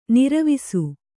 ♪ niravisu